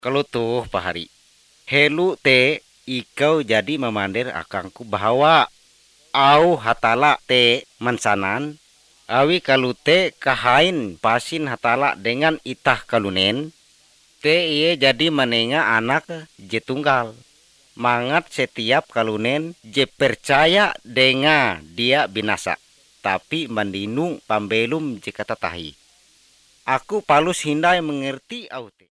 Messages from native believers for evangelism, growth and encouragement.